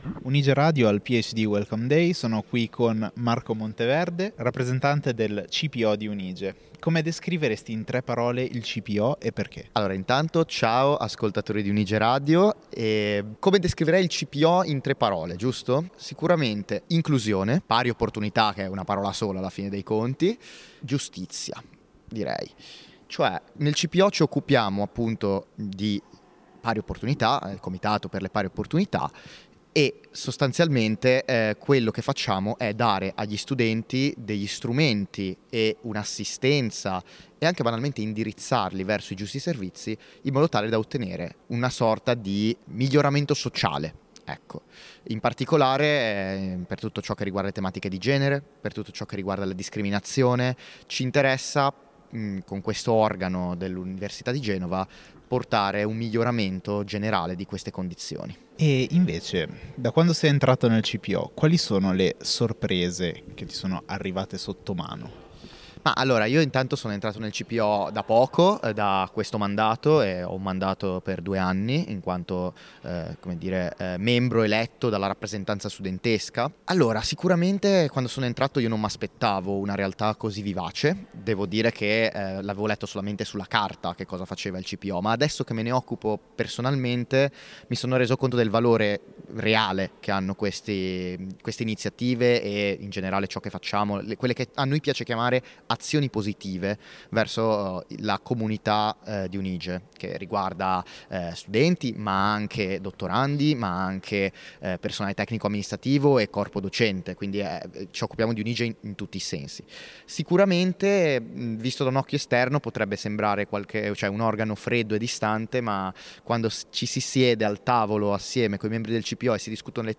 Un’intervista che racconta il CPO come uno spazio vivo, attivo e orientato a migliorare concretamente le condizioni di studenti, dottorandi e personale universitario.